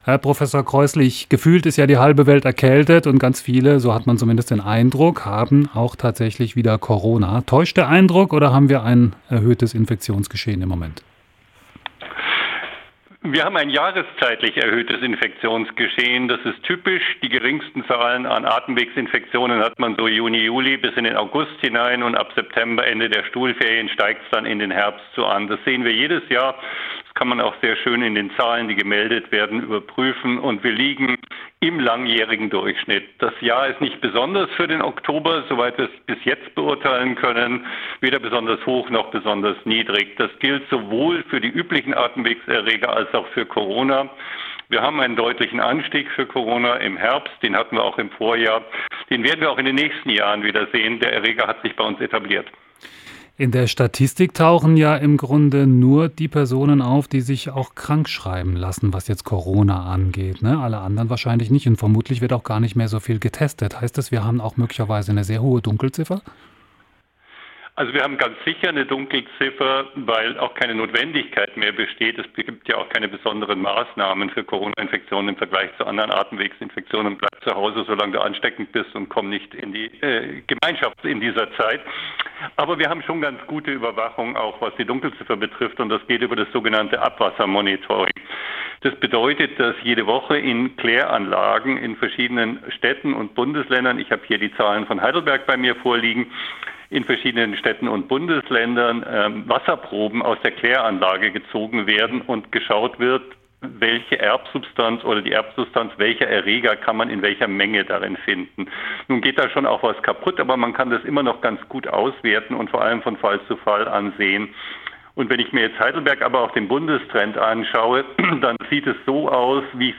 Virologe im Interview zu Corona-Infektionen: "Der Erreger hat sich bei uns etabliert"